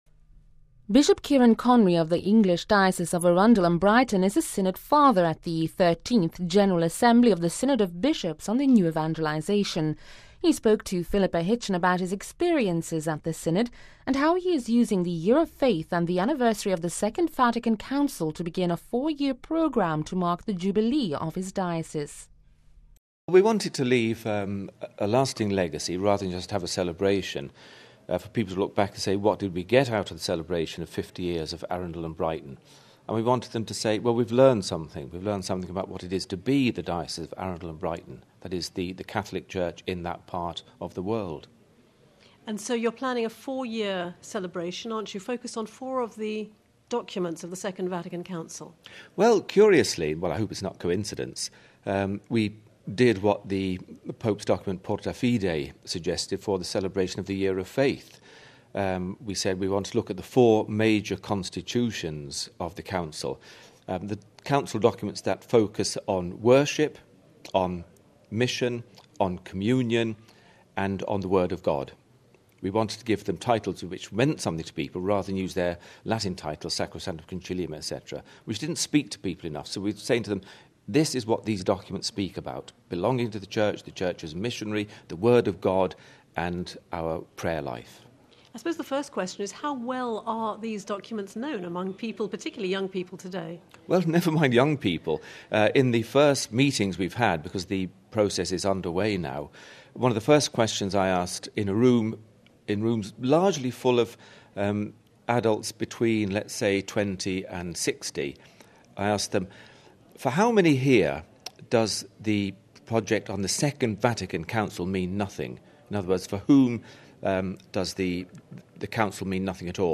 He spoke to Vatican Radio about the problems facing the Church, especially with “lapsed” or “non-practicing” Catholics.